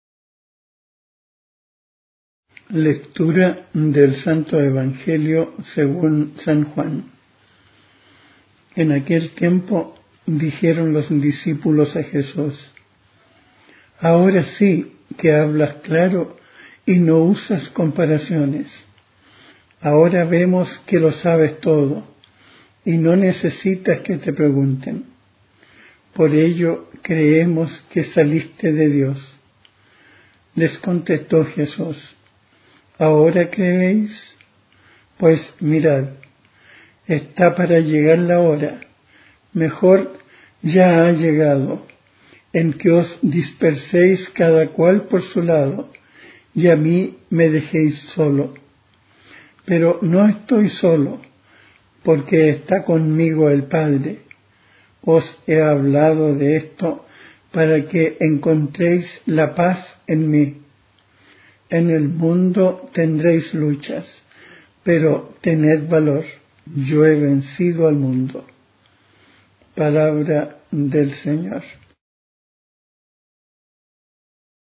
Lectura del libro de los Hechos de los apóstoles (19,1-8):